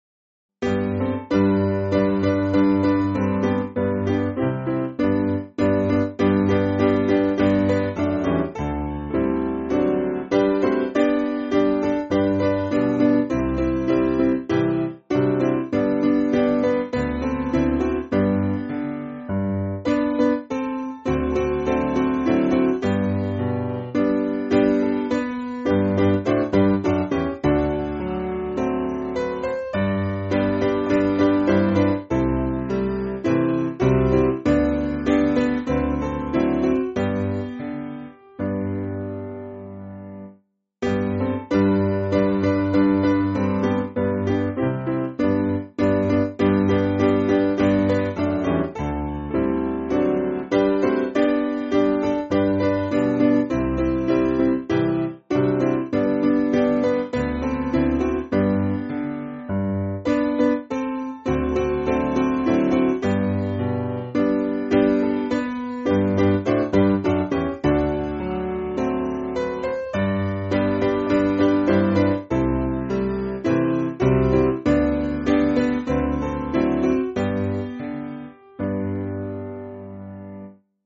Simple Piano
(CM)   4/G